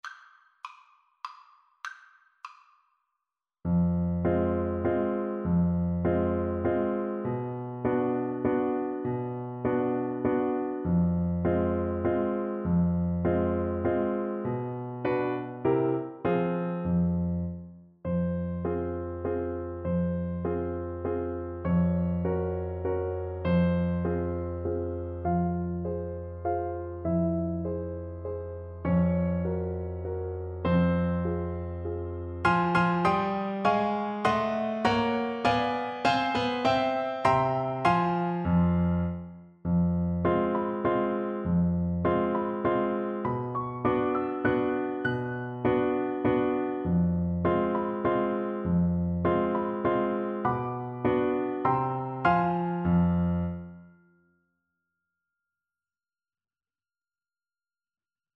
3/4 (View more 3/4 Music)
Sinister = c.100
Classical (View more Classical Clarinet Music)